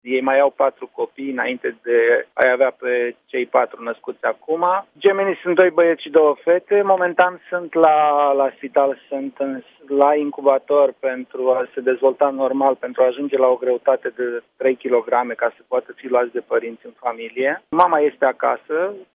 Am stat de vorbă cu primarul comunei ieșene Lespezi – Ioan Lazăr.